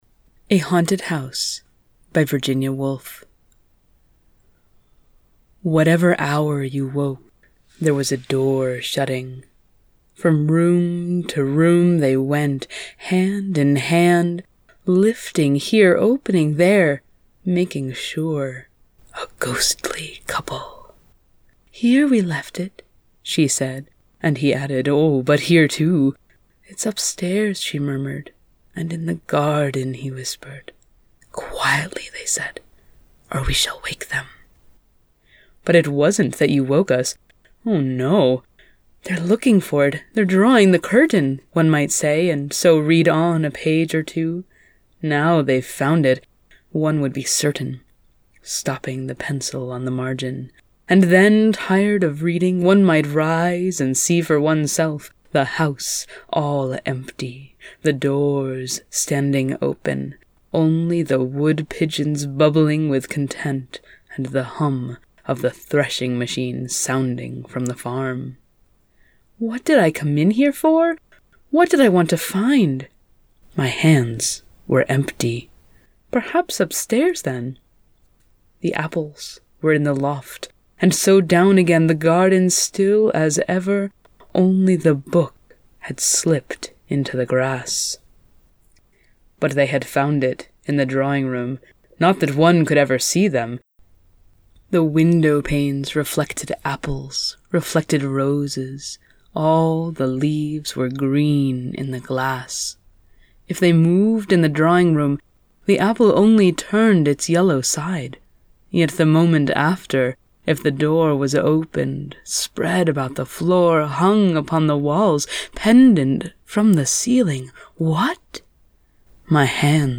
Narrated version of Woolf’s “A Haunted House”